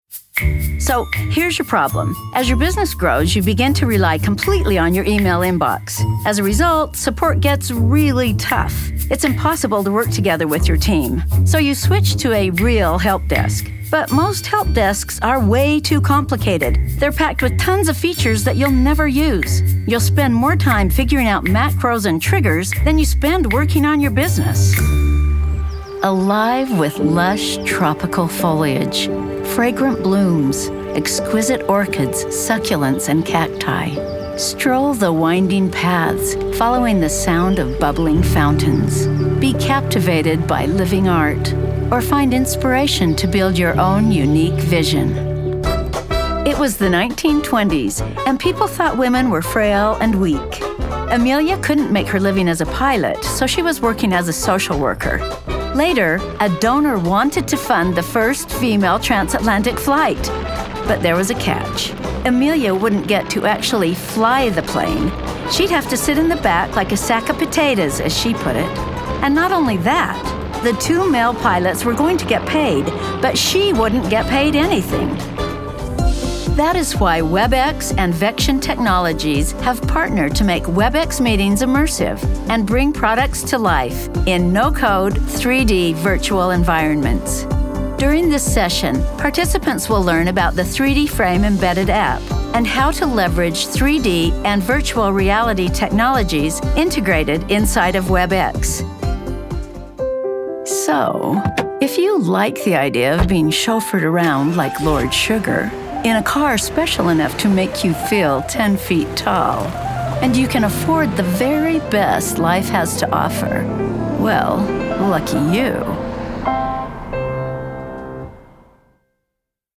Voiceover artist - The Voice of Sunshine